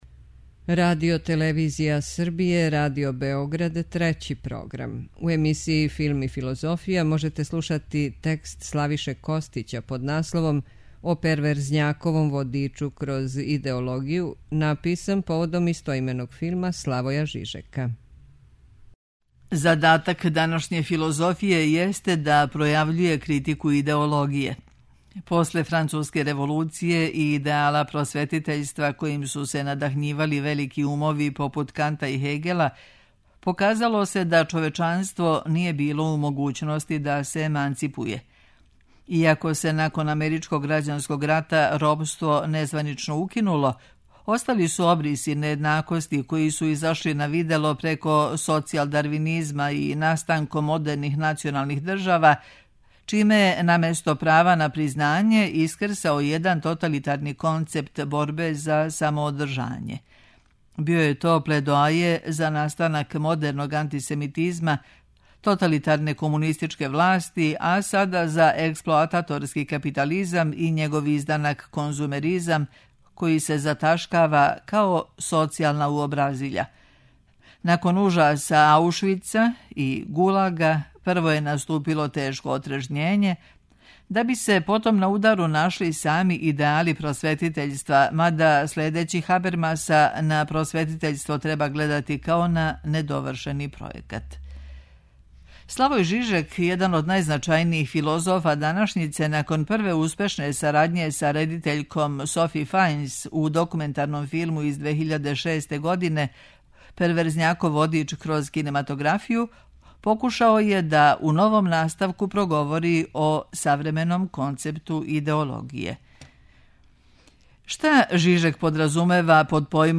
преузми : 9.65 MB Рефлексије Autor: Уредници Трећег програма У емисијама РЕФЛЕКСИЈЕ читамо есеје или научне чланке домаћих и страних аутора.